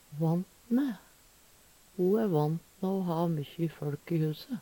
vant mæ - Numedalsmål (en-US)